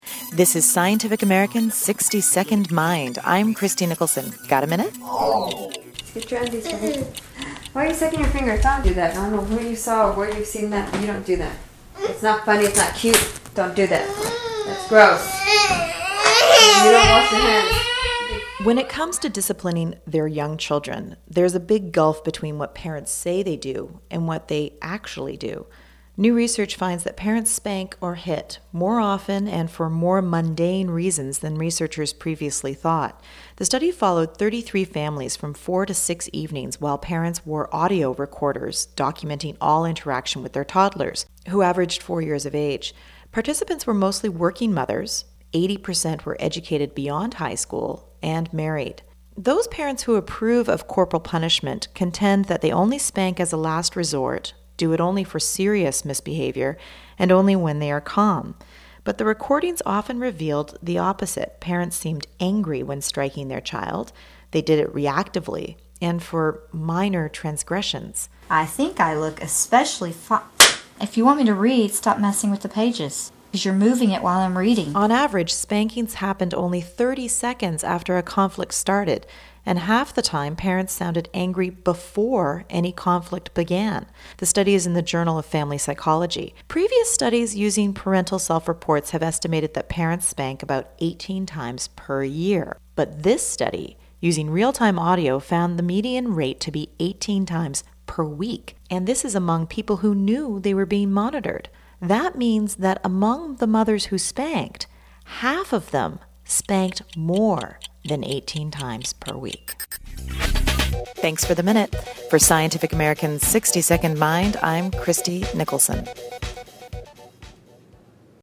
[Audio of mother and child]